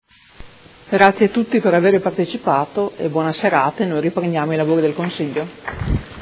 Seduta del 24/01/2019. Ringraziamento agli ospiti